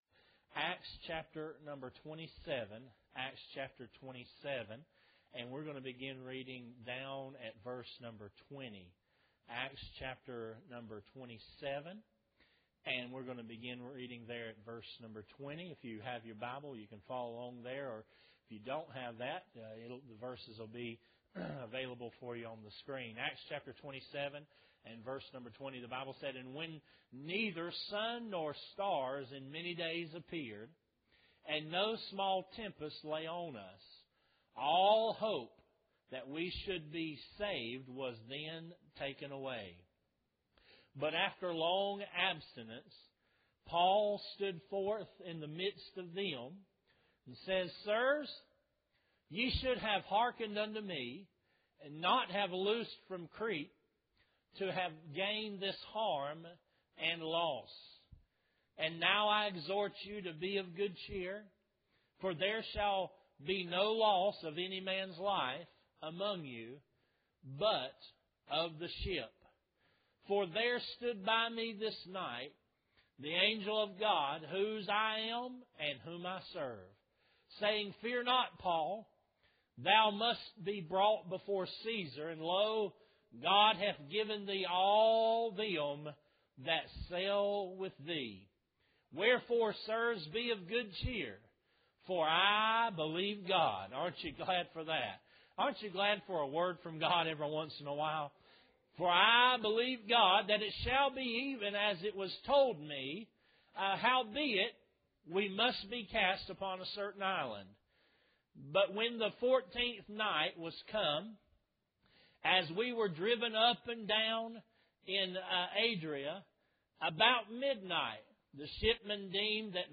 Sermons | Maranatha Baptist Church - Dallas, NC